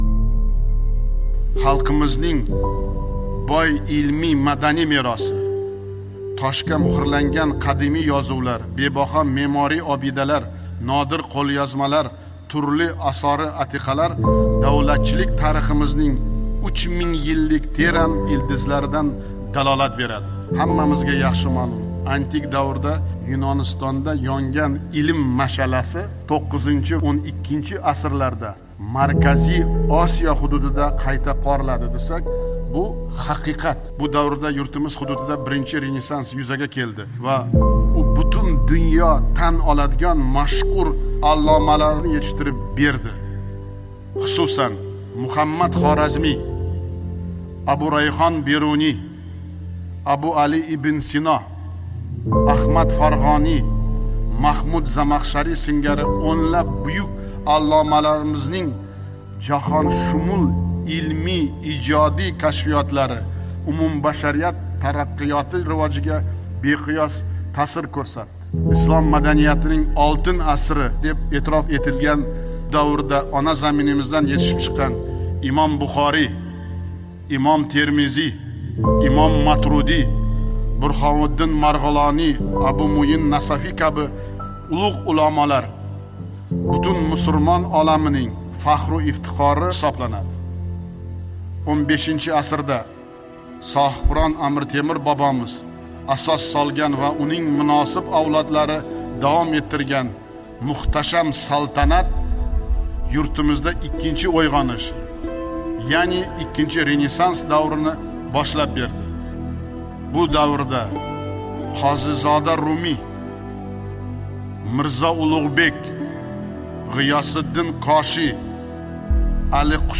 Umumiy renesans haqida Prezident nutqi
Muallif: Shavkat Mirziyoyev